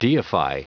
Prononciation du mot deify en anglais (fichier audio)
Prononciation du mot : deify